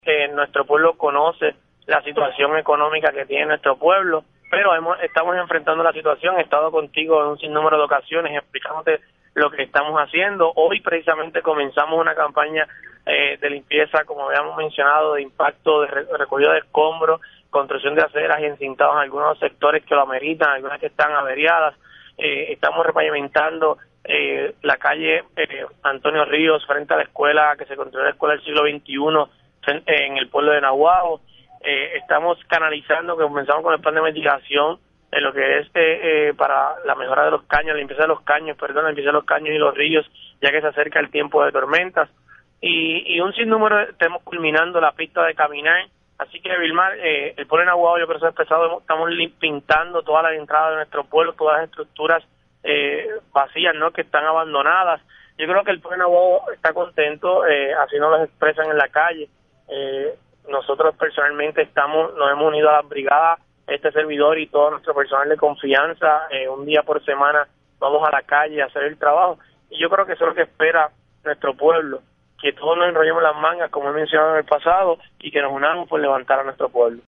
EN HORAS DE LA MANANA EL ALCALDE DE NAGUABO, NOE MARCANO, HABLO EN TRAS LA NOTICIA SOBRE LAS MEDIDAS QUE HA TOMADO EN SUS YA CASI 100 DIAS EN LA ALCALDIA PARA ENFRENTAR LOS PROBLEMAS ECONOMICOS DEL MUNICIPIO.